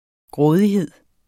Udtale [ ˈgʁɔːðiˌheðˀ ]